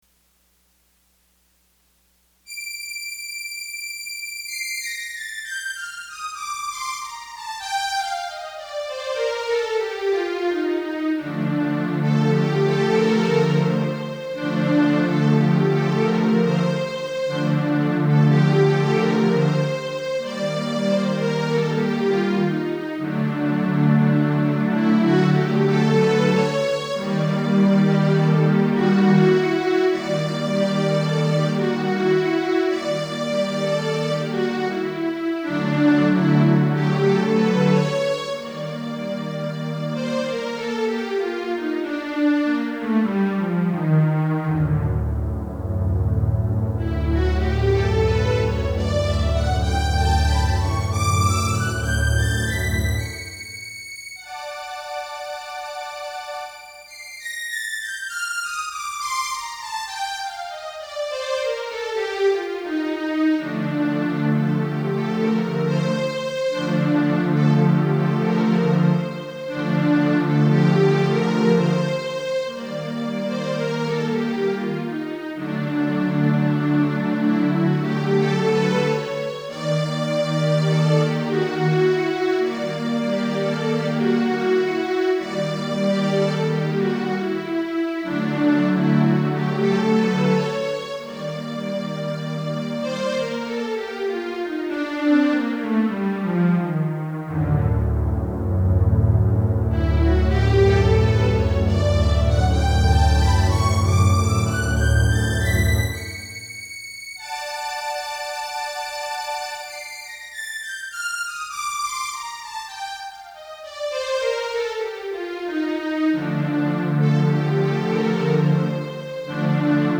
とっても短い曲？ですがキーボードでカデンツァ？を付けて３回くり返してオルガン調、に弾いたものをご試聴下さい。 夢で聴いたことと、また、音階を左右反転（鏡音楽の調子）してメロデイを繰り返しているので、タイトルを荘子の、周荘胡蝶の夢、にちなんで「胡蝶の夢」ということにしました。